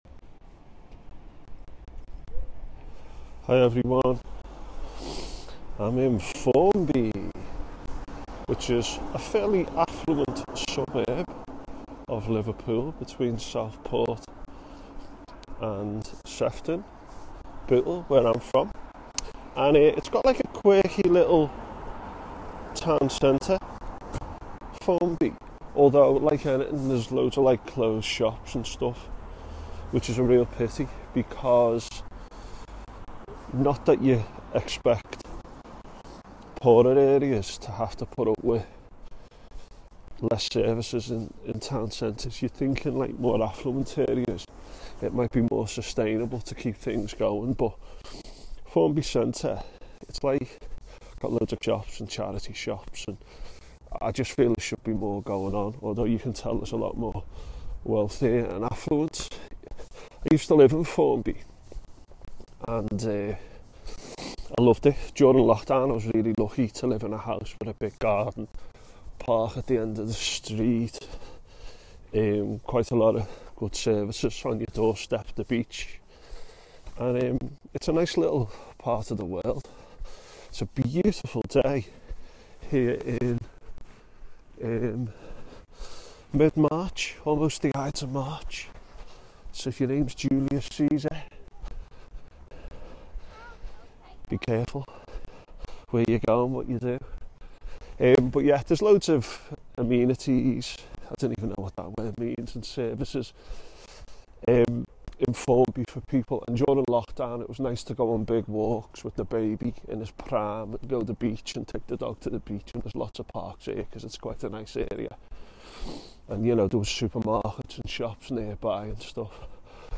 Apologies for fuzzy microphone!